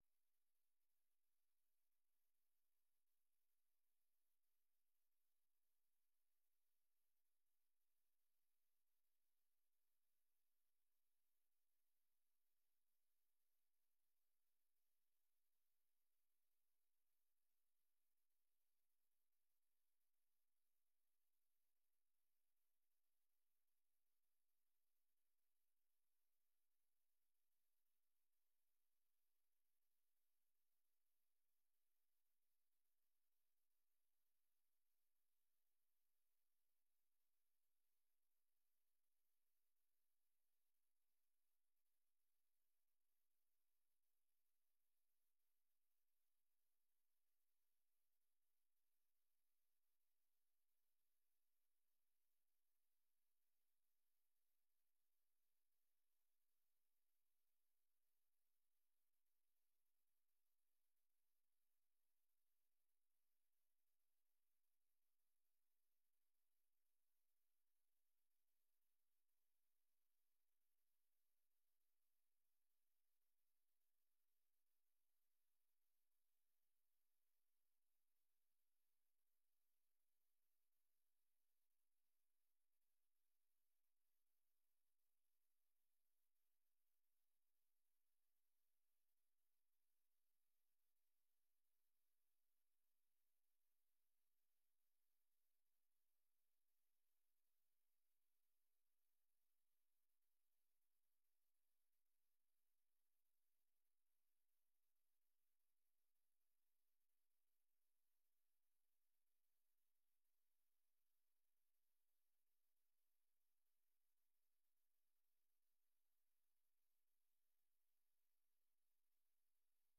Hierbij nodig ik u uit tot het bijwonen van een openbare vergadering van de commissie op maandag 10 februari 2025, om 19,30 uur in kamer 63 van het gemeentehuis te Emmeloord.